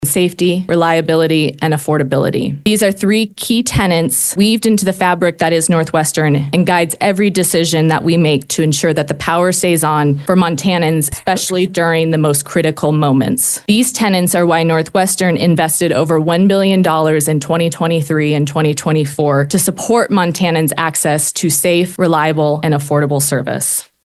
NorthWestern Energy Rate Hike Proposal Under Review by Public Service Commission Amid Two-Week Hearing